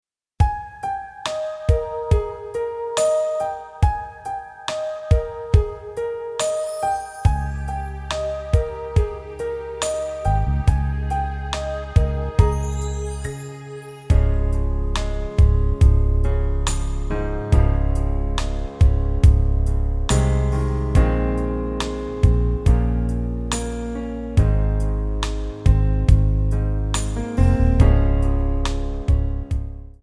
C#
Backing track Karaoke
Pop, Duets, 1990s